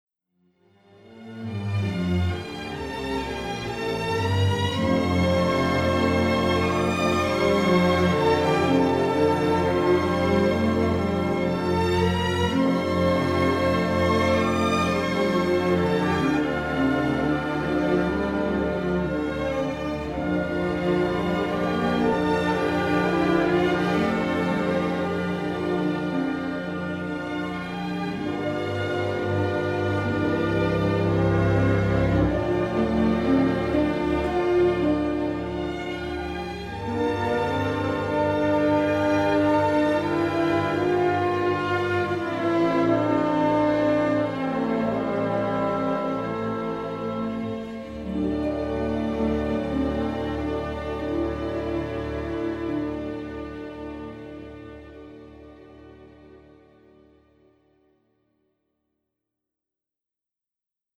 unabashedly romantic
suspenseful and brooding, pastoral and uplifting